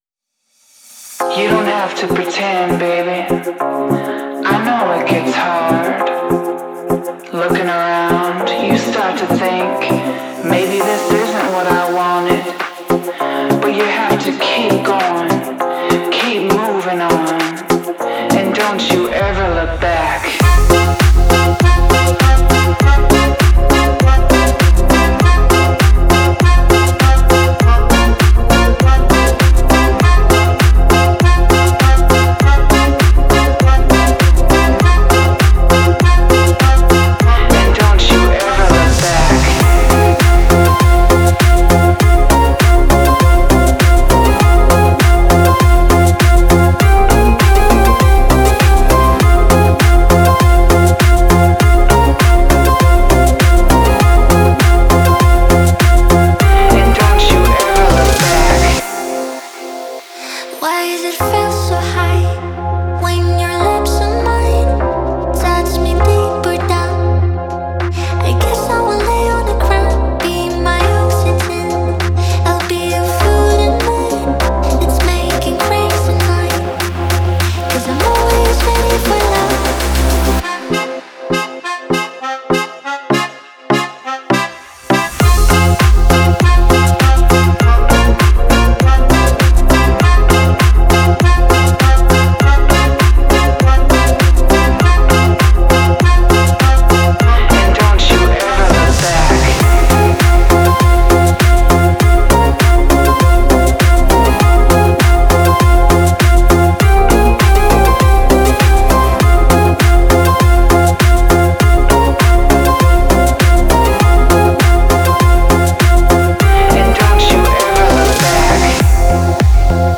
веселая музыка